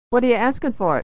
Unstressed 'for' is reduced = /fər/